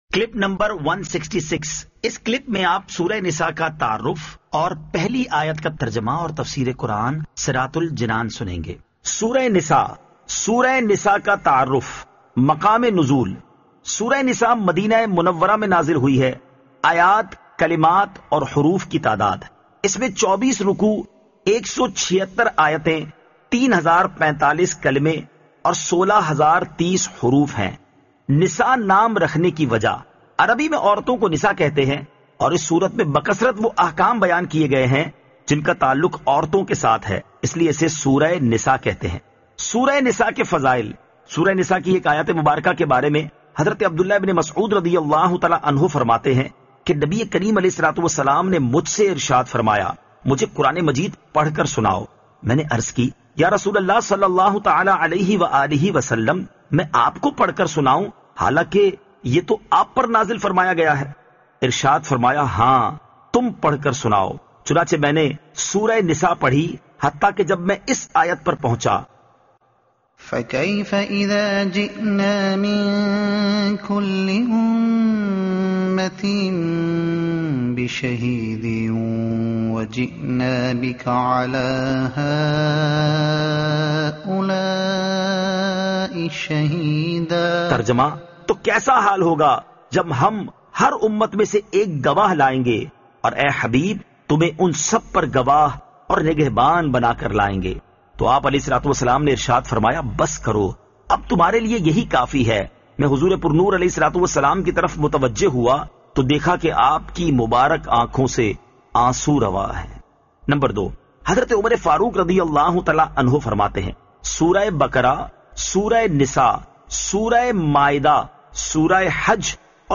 Surah An-Nisa Ayat 01 To 01 Tilawat , Tarjuma , Tafseer